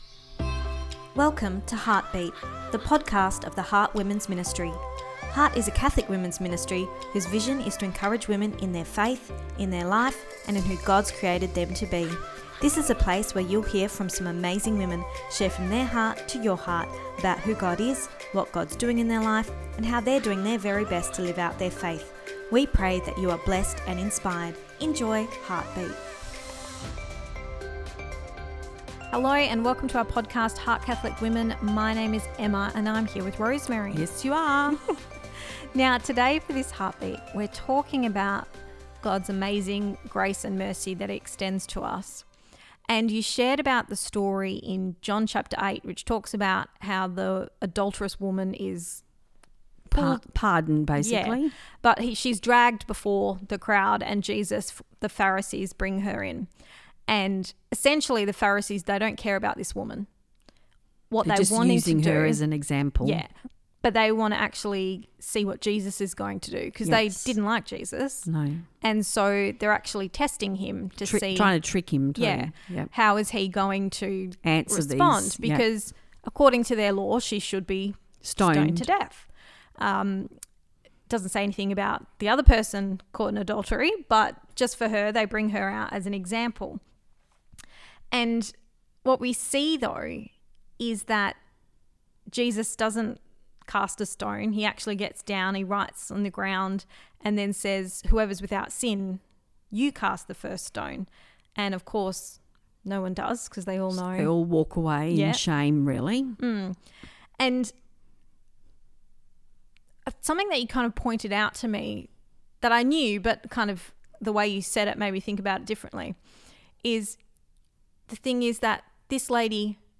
Ep253 Pt2 (Our Chat) – God is So Gracious